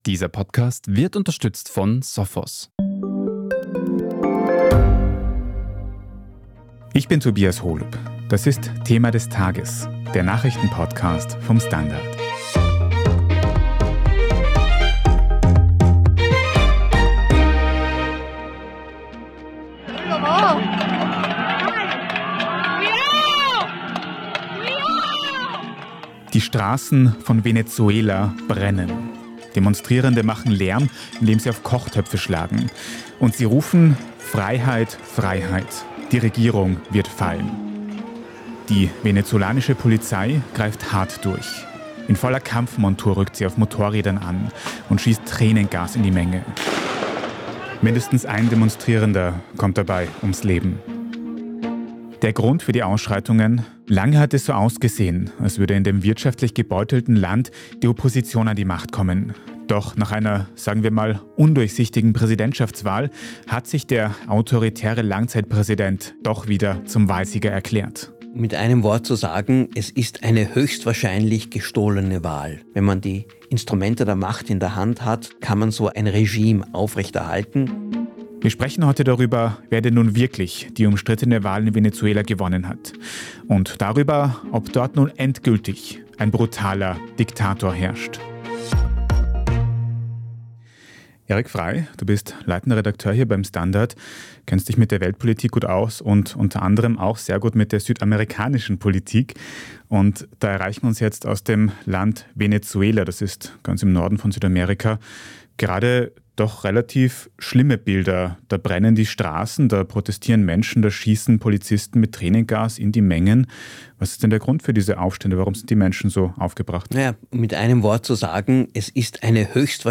Demonstrierende machen Lärm, indem sie auf Kochtöpfe schlagen.